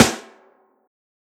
SNARE_SAVE_ME.wav